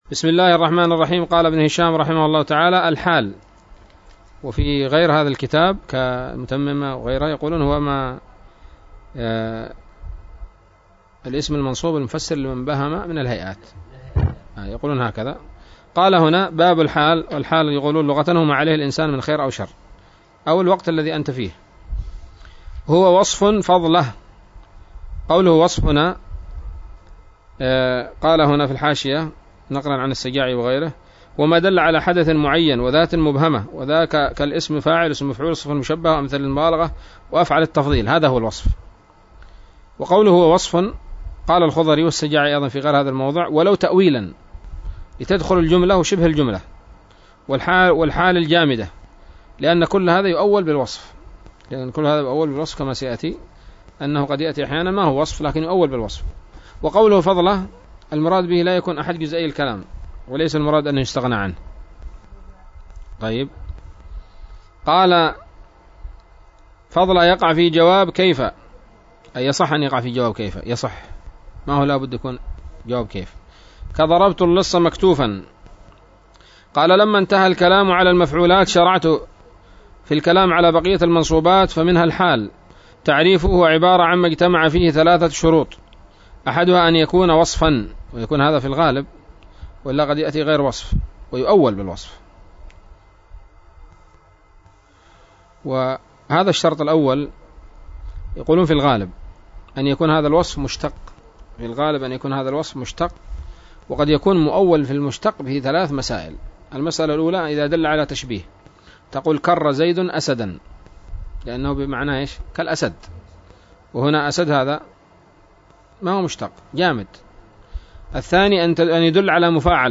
الدرس السابع والتسعون من شرح قطر الندى وبل الصدى